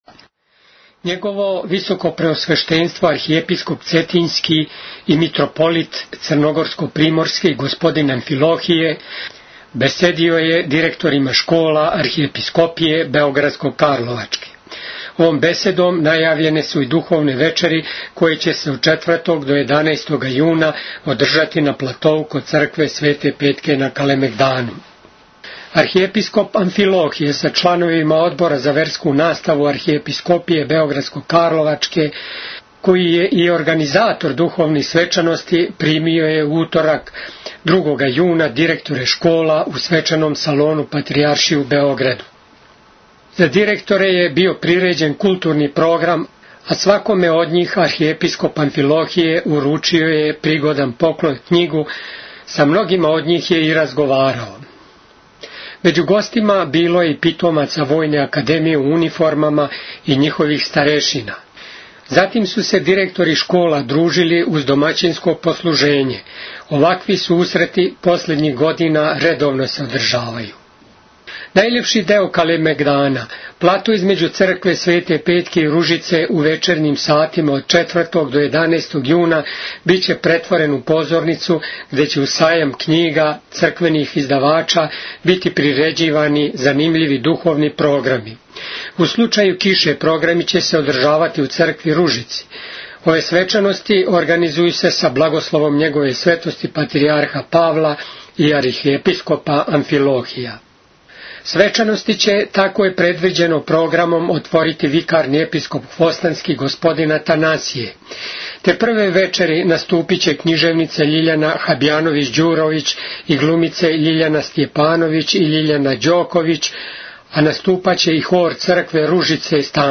Пријем директора основних и средњих школа у Двору Српске Патријаршије
Данас је у Двору Српске Патријаршије одржан свечани пријем поводом осам школских година успјешне сарадње у области вјерске наставе у основним и средњим школама на подручју Архиепископије београдско - карловачке.